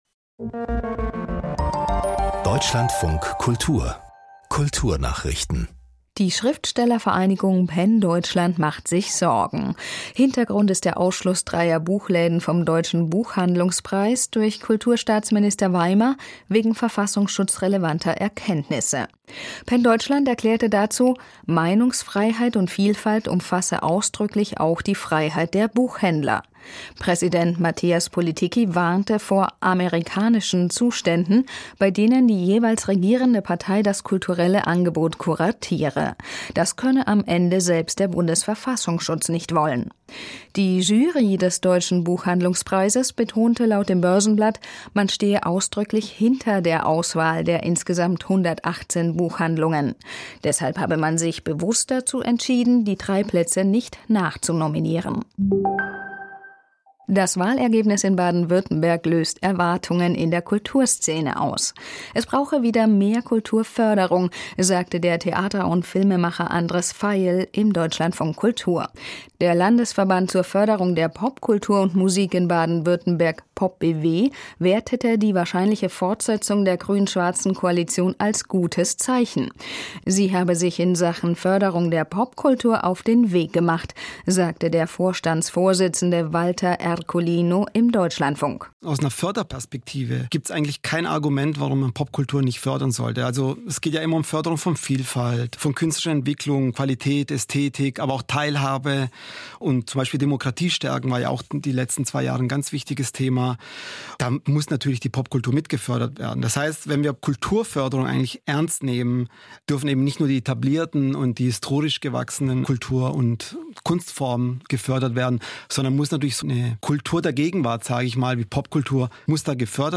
Kulturnachrichten